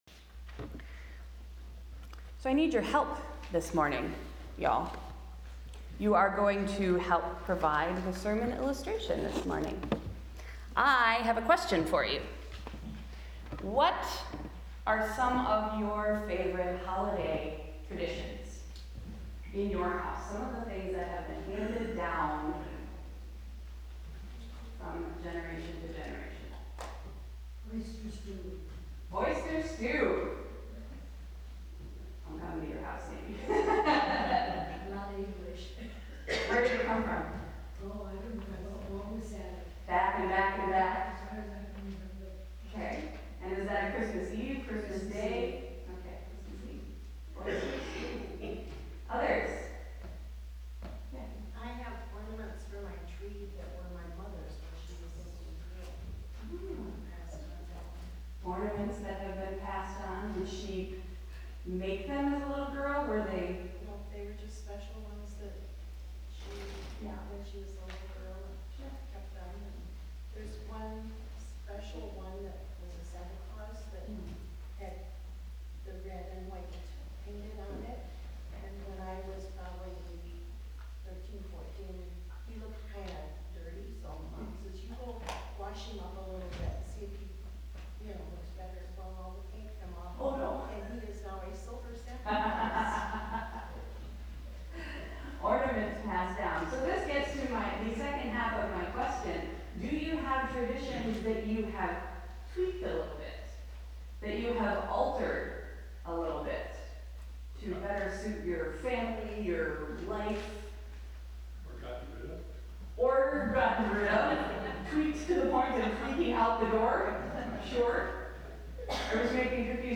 Sunday’s sermon: Old Thing, New Thing, Bold Thing, True Thing